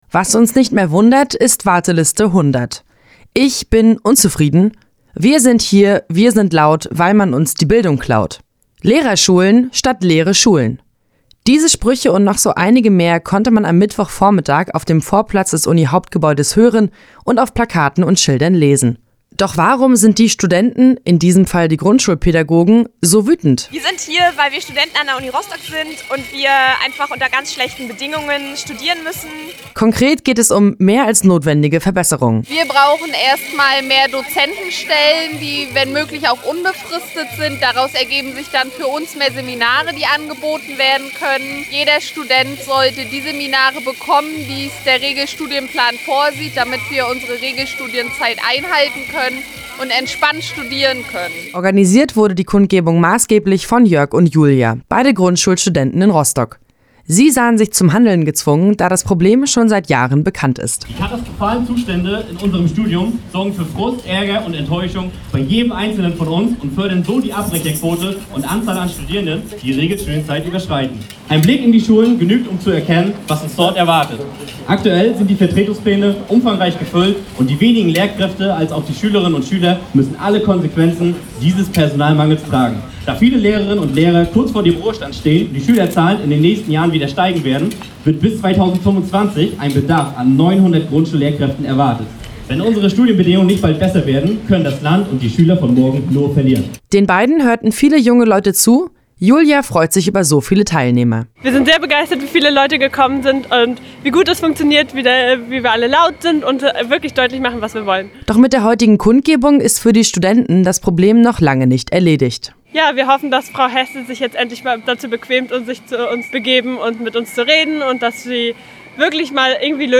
Wer am Mittwoch vormittag durch die Rostocker Innenstadt spaziert ist, konnte schon von weitem lautes Rasseln und Pfeifen hören.